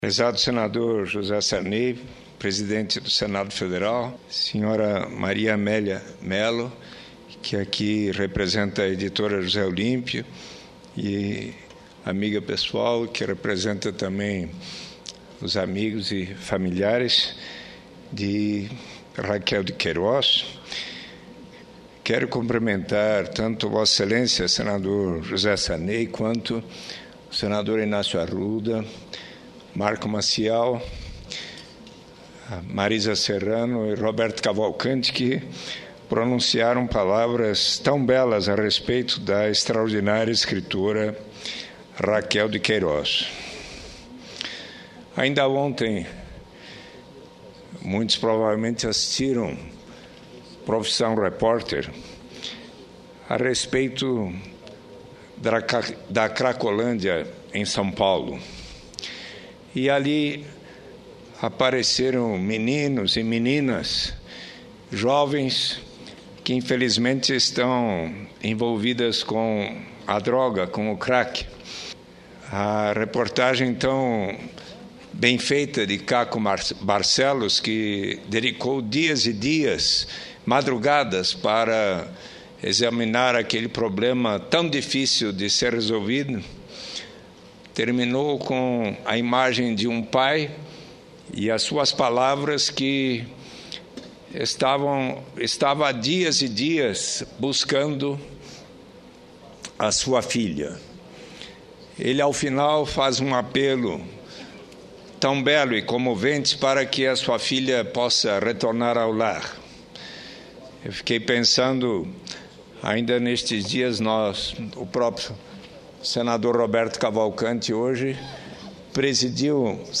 Discurso do senador Eduardo Suplicy